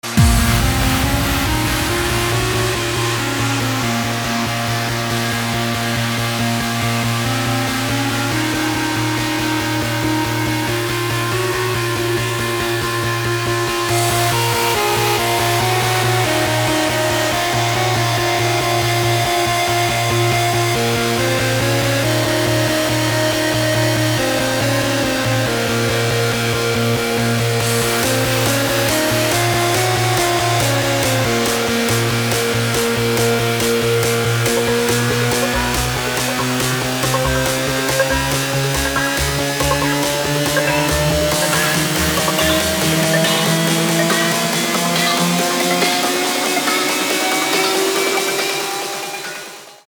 громкие
dance
Electronic
без слов
Trance